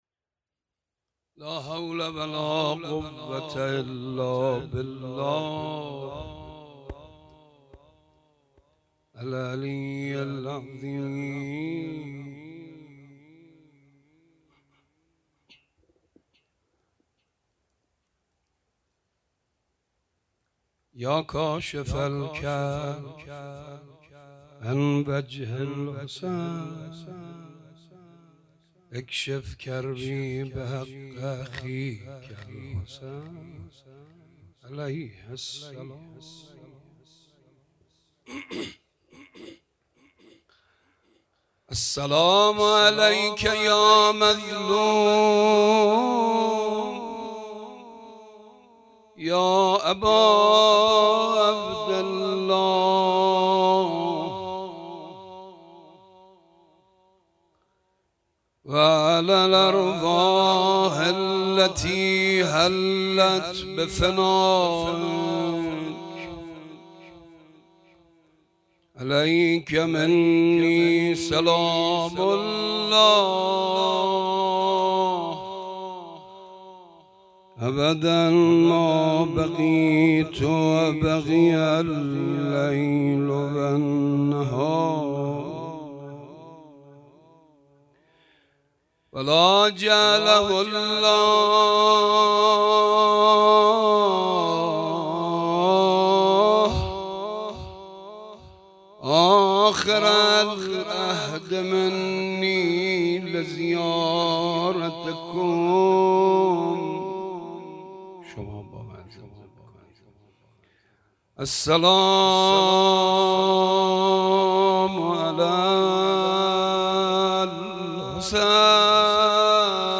شب نهم محرم ۱۴۴۴